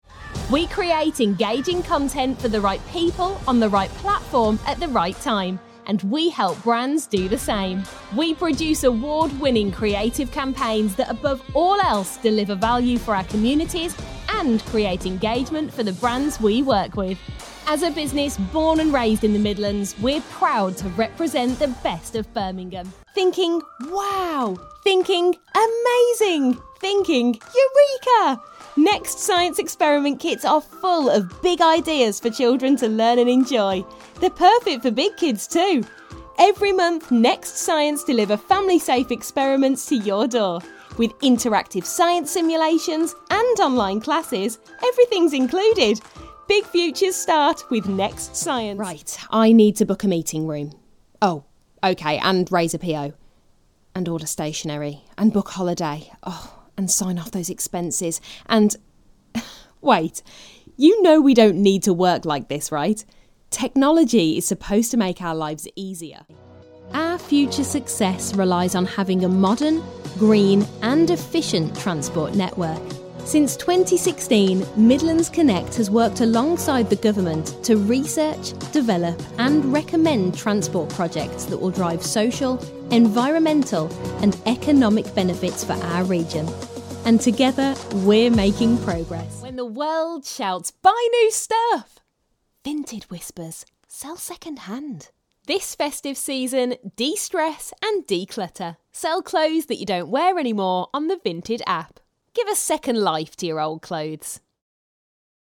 young female British corporate TV Presenter